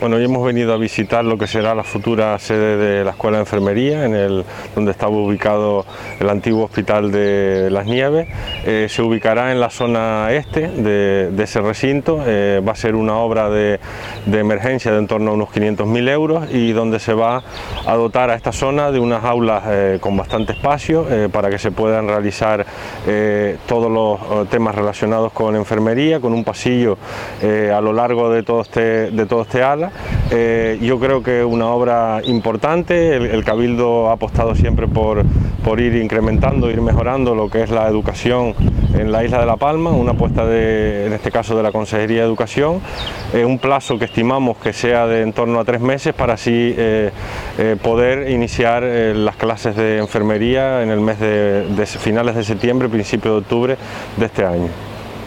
Declaraciones de Borja Perdomo, consejero de Infraestructuras sobre la nueva se…